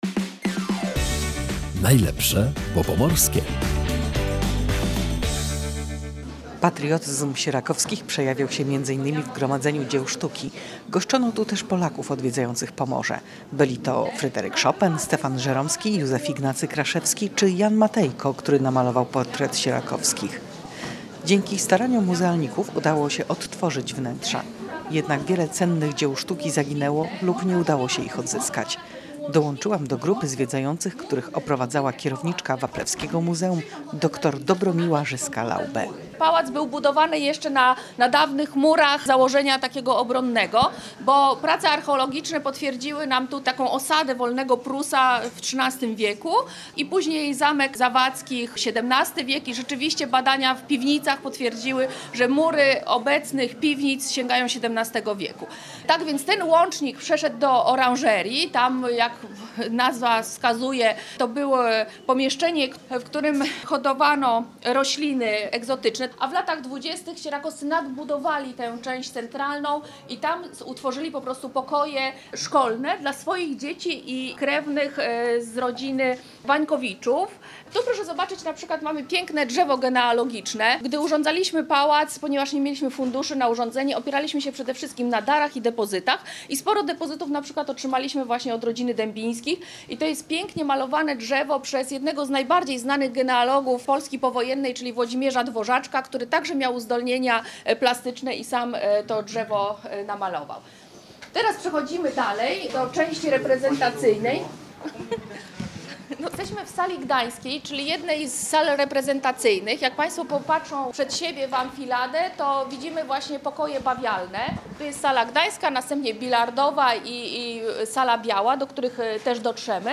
zobaczyła zabytkowe wnętrza z grupą zwiedzających